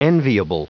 Prononciation du mot enviable en anglais (fichier audio)